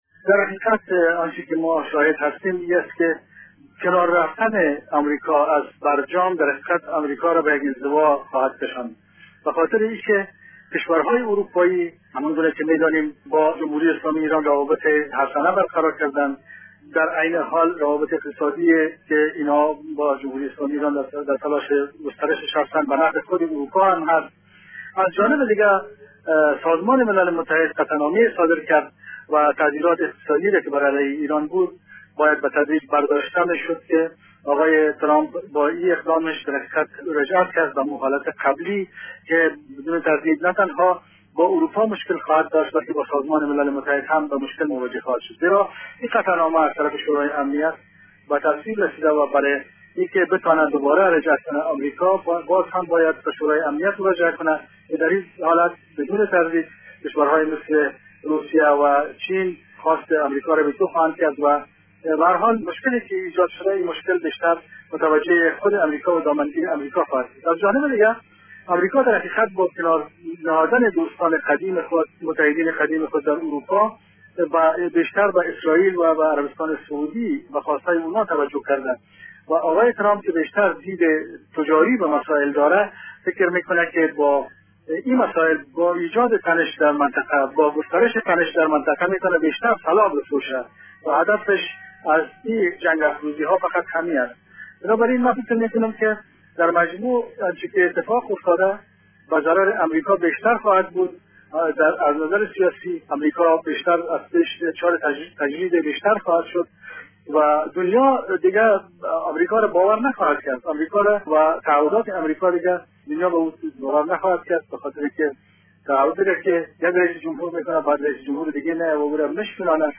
کارشناس وفعال سیاسی می گوید